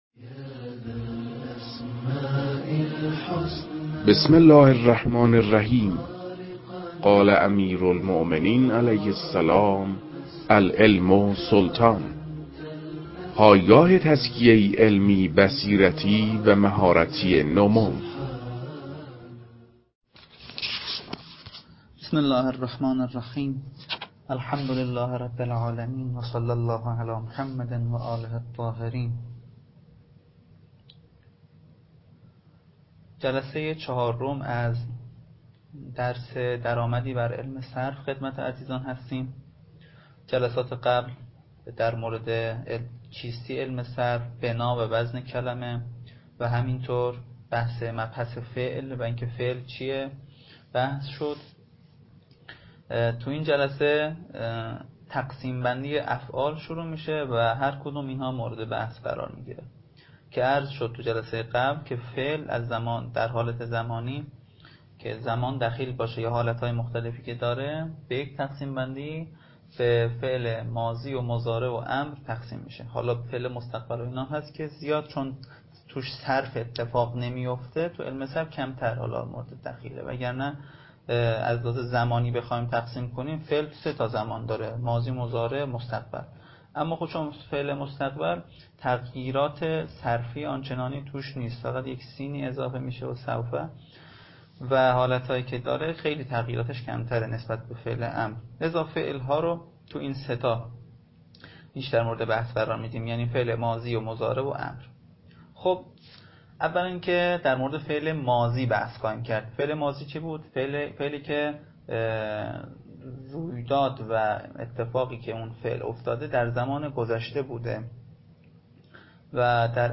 در این بخش، کتاب «درآمدی بر صرف» که اولین کتاب در مرحلۀ آشنایی با علم صرف است، به صورت ترتیب مباحث کتاب، تدریس می‌شود.
در تدریس این کتاب- با توجه به سطح آشنایی کتاب- سعی شده است، مطالب به صورت روان و در حد آشنایی ارائه شود.